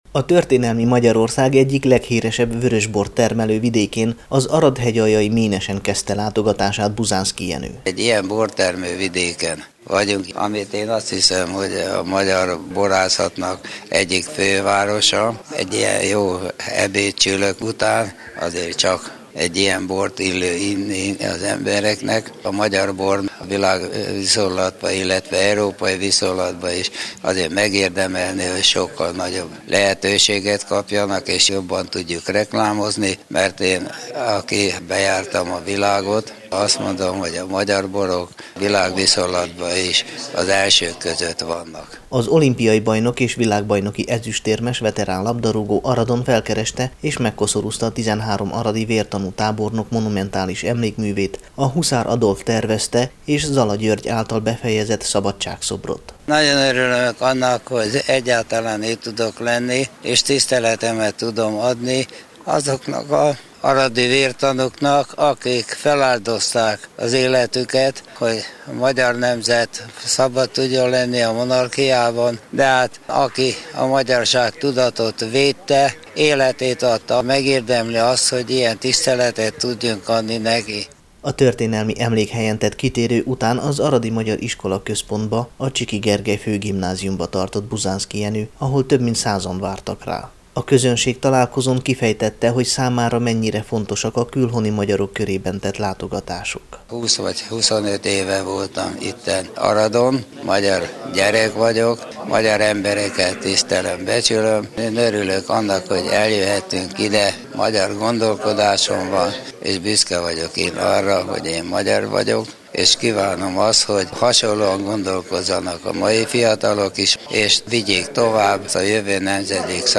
Buzánszky Jenő 2013. február 6-án Aradra és Hegyaljára látogatott, délután pedig – az Aradi Hírek meghívására – a Csiky Gergely Főgimnázium dísztermében közönségtalálkozót tartott.